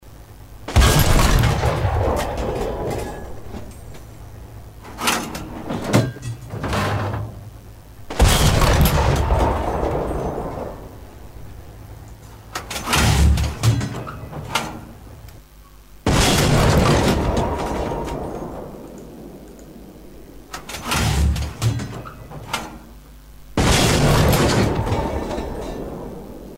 Реалистичные эффекты с хорошей детализацией низких частот.
Выстрел из 76-мм орудия